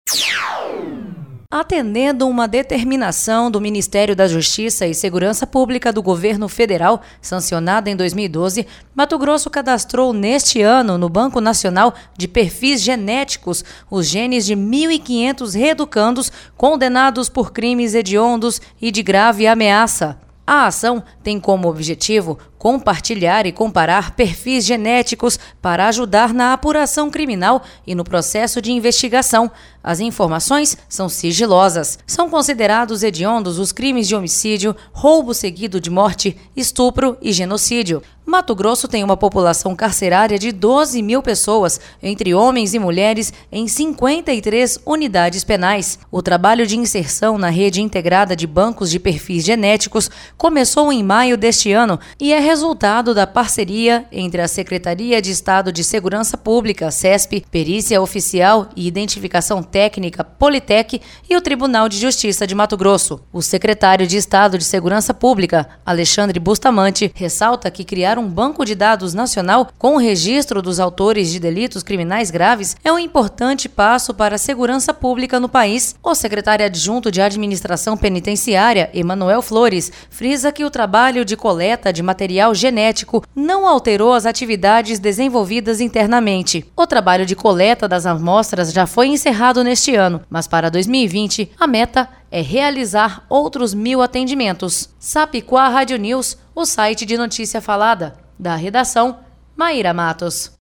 Boletins de MT 03 dez, 2019